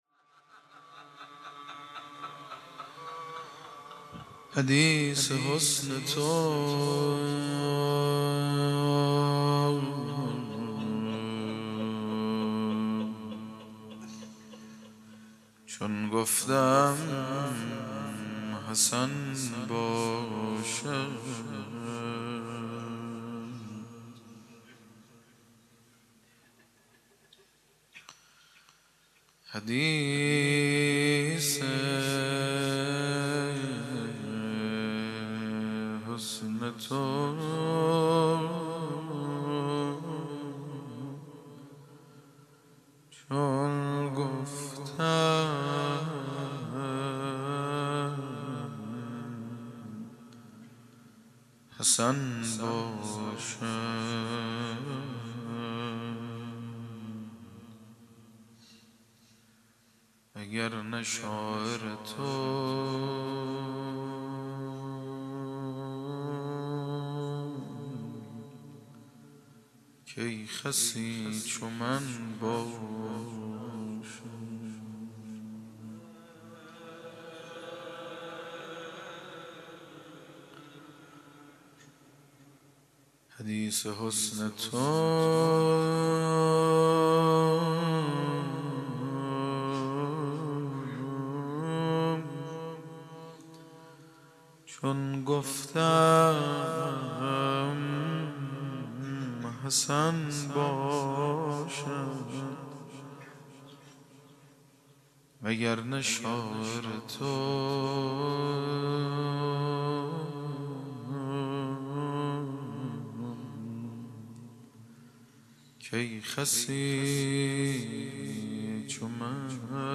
01-Rozeh.mp3